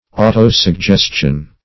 Autosuggestion \Au`to*sug*ges"tion\, n. [Auto- + suggestion.]